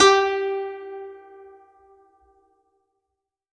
52-str13-zeng-g3.aif